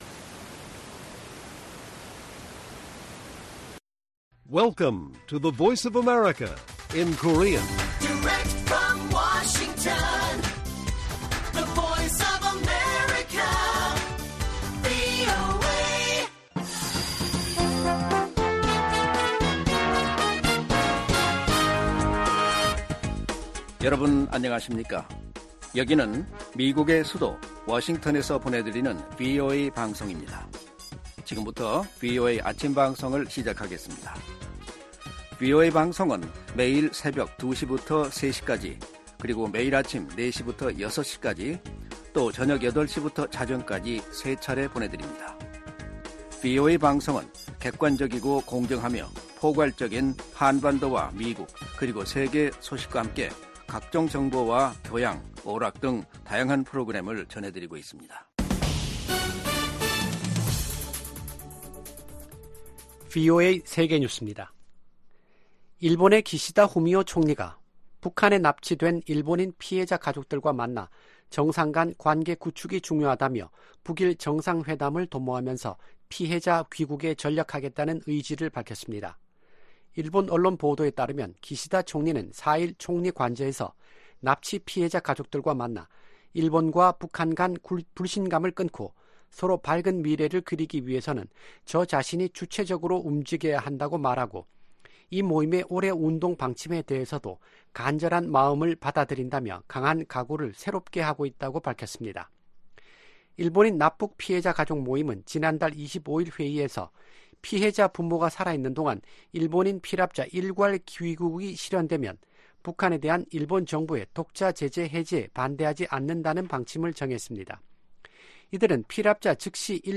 세계 뉴스와 함께 미국의 모든 것을 소개하는 '생방송 여기는 워싱턴입니다', 2024년 3월 5일 아침 방송입니다. '지구촌 오늘'에서는 중국 최대 정치 행사인 양회 개막 소식 전해드리고, '아메리카 나우'에서는 워싱턴 D.C. 공화당 예비선거에서 니키 헤일리 전 유엔 대사가 승리한 이야기 살펴보겠습니다.